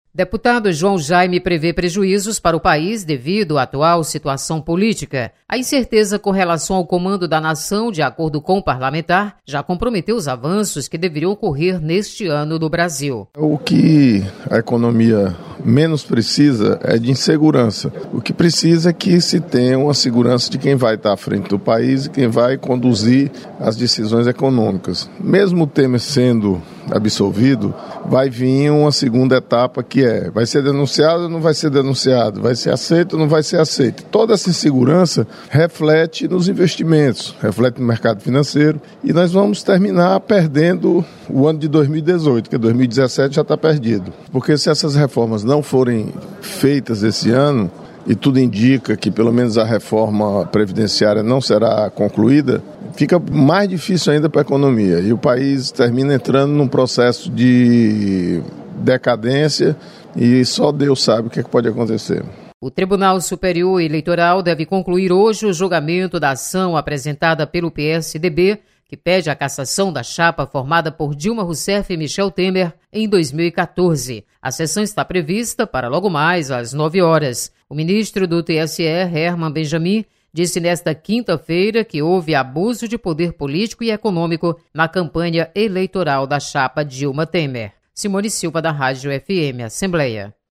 Deputado João Jaime fala sobre incertezas na economia brasileira.